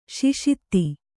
♪ śiṣrūṣe